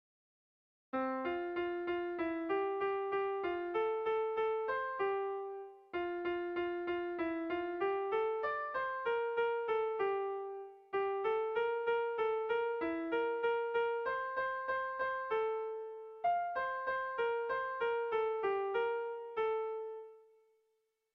Zortziko txikia (hg) / Lau puntuko txikia (ip)
ABDE